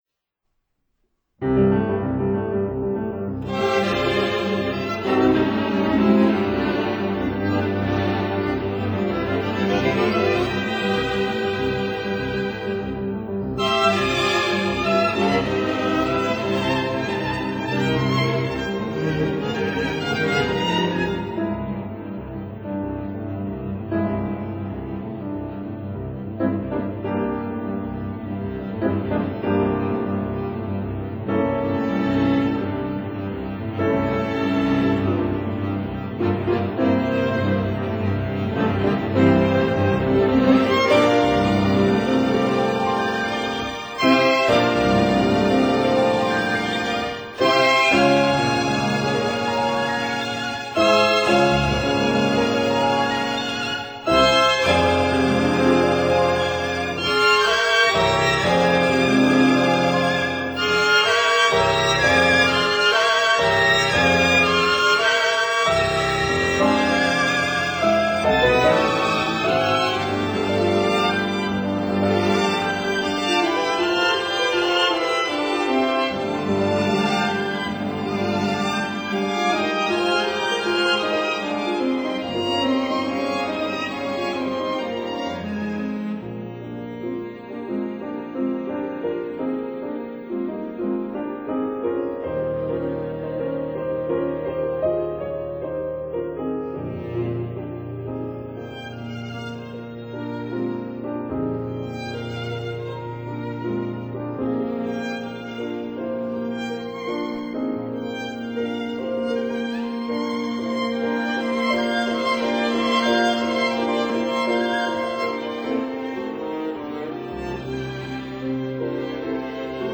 violins
viola
cello
piano